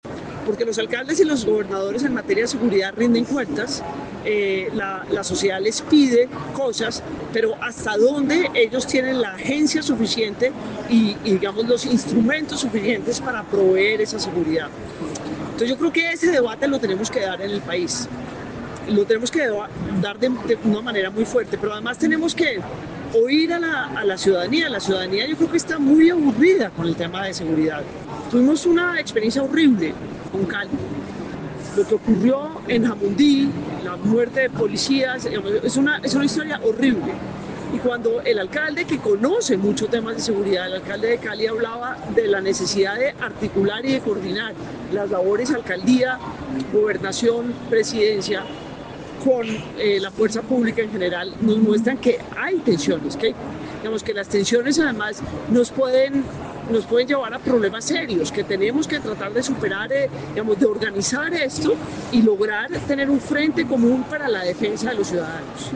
En entrevista con Caracol Radio en la feria de Ulibro en Bucaramanga habló de los recientes hechos violentos ocurridos en Antioquia, Cali y en Jamundí, donde fueron asesinados policías muestran la falta de articulación entre alcaldías, gobernaciones, Presidencia y Fuerza Pública.